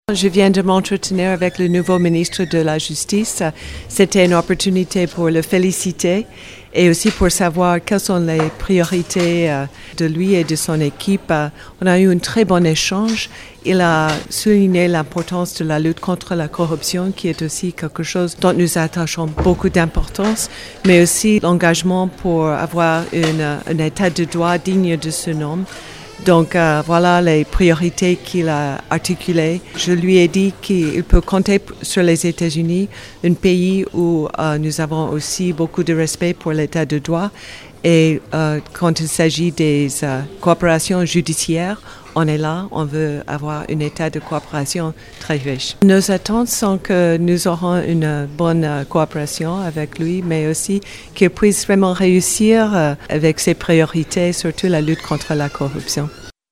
Les propos de Lucy Tamlyn: